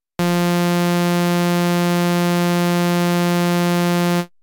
A standard sawtooth oscillator is:-
Sawtooth]
Saw.mp3